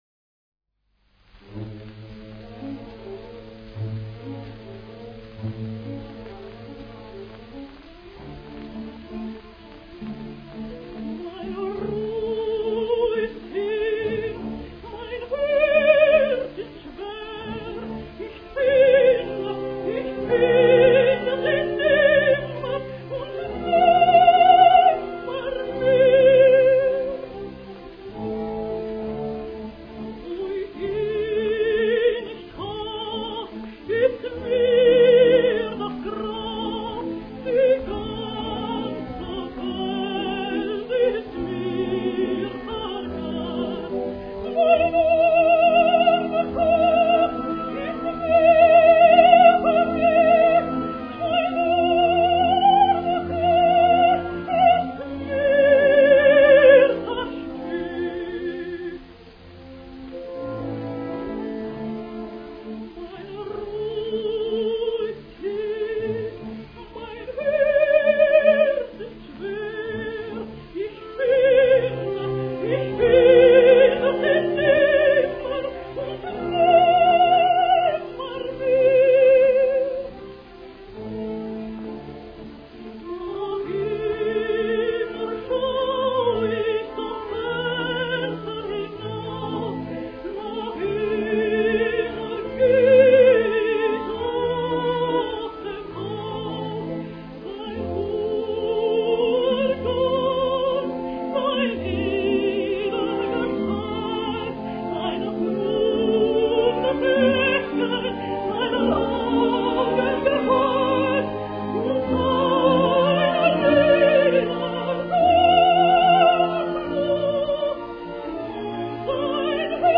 Listen to Seinemeyer's amazing intensity in